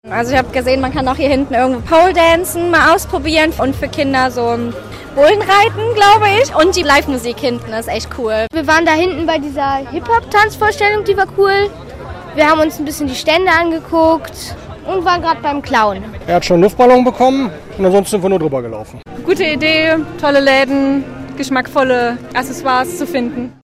UmfrageDas sagt Ihr zum Hammer-Straßen-Fest